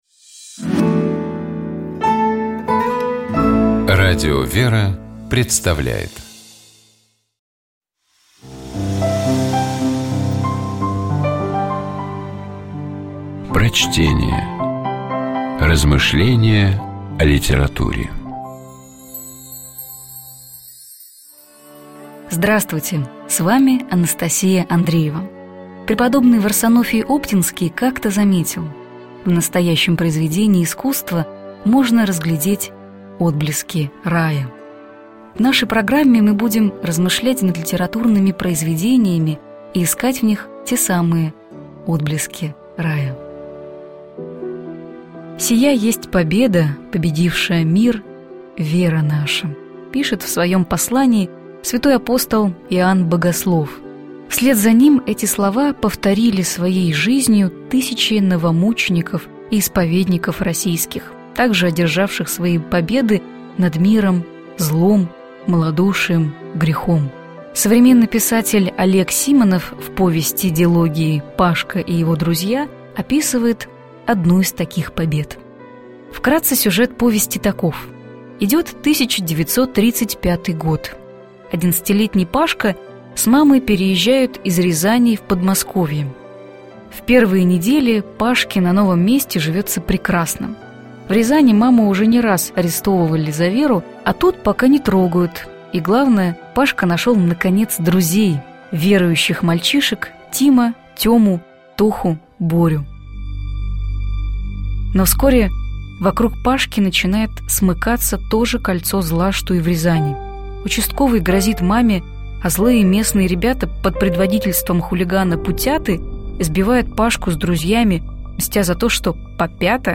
Prochtenie-O_-Simonov_-Pashkiny-krylja-Pobeda-nad-malodushiem.mp3